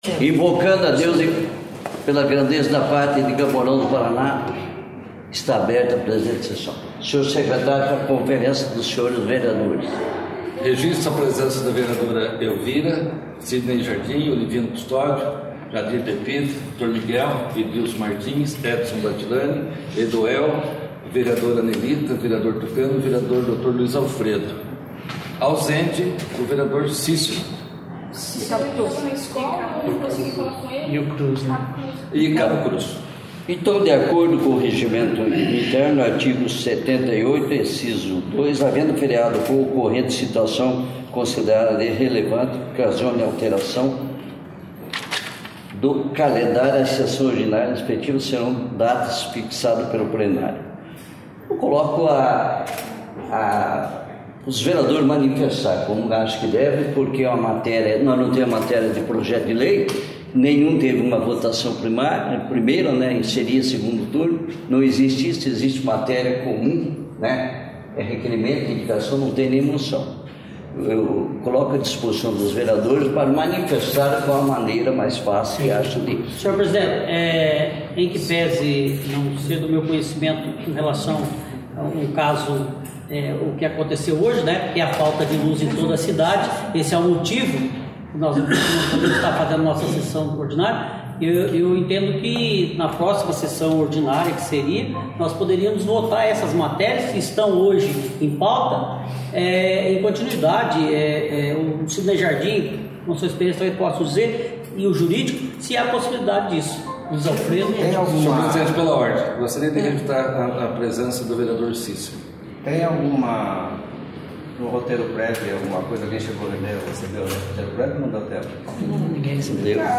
2ª Sessão Ordinária